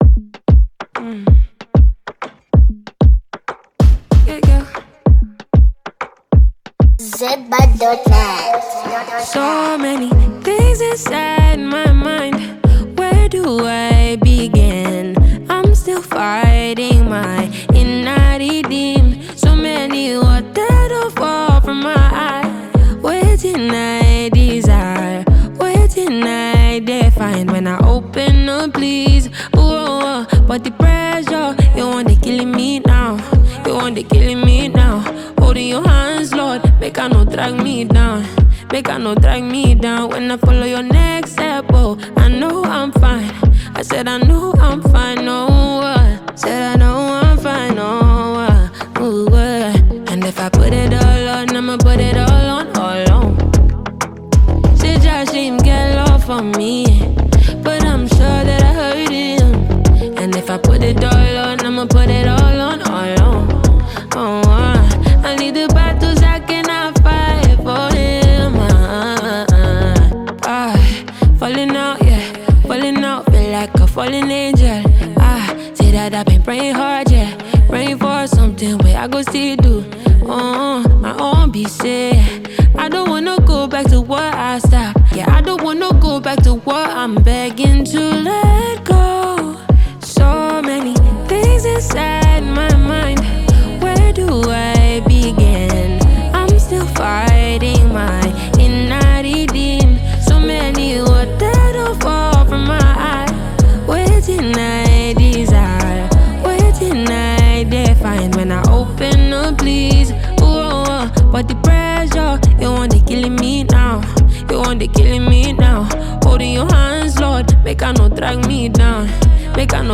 Genre: Afrobeat Year